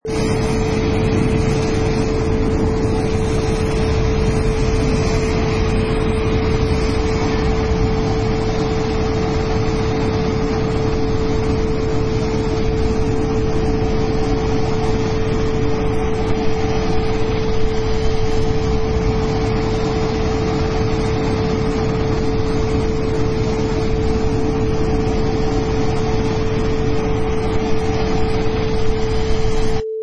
Wav: Jet Engine Runway 1
Powerful jet engine recording taken on runway only 50 feet from plane
Product Info: 48k 24bit Stereo
Category: Vehicles / Aircraft - Planes
Try preview above (pink tone added for copyright).
Jet_Engine_Runway_1.mp3